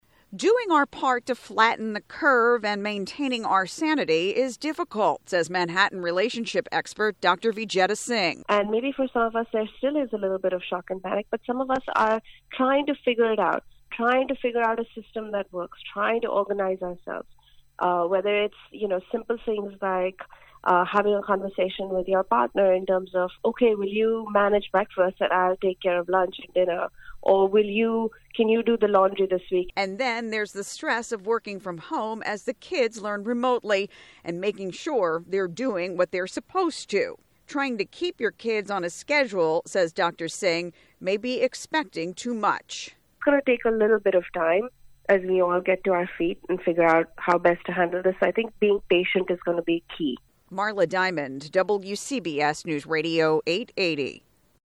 Radio & Podcast